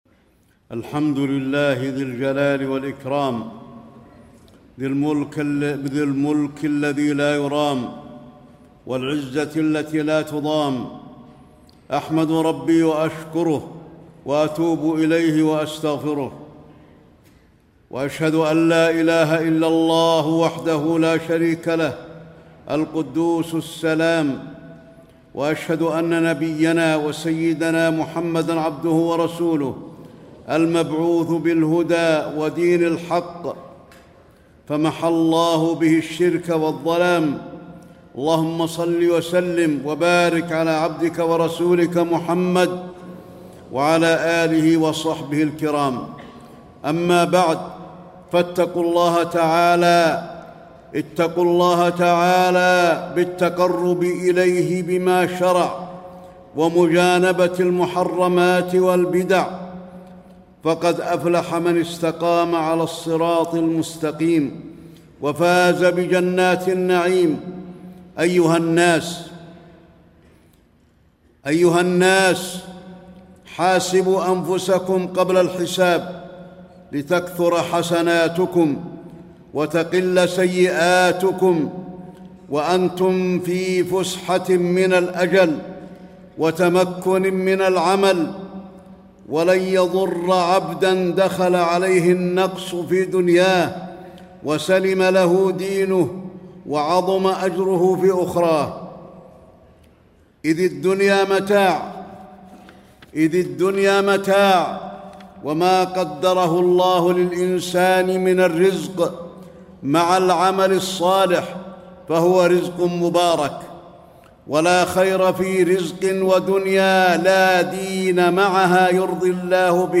تاريخ النشر ٧ ربيع الثاني ١٤٣٥ هـ المكان: المسجد النبوي الشيخ: فضيلة الشيخ د. علي بن عبدالرحمن الحذيفي فضيلة الشيخ د. علي بن عبدالرحمن الحذيفي الإكثار من الأعمال الصالحة